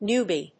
/ˈnjuːbi(米国英語), ˈnu:ˌbi:(英国英語)/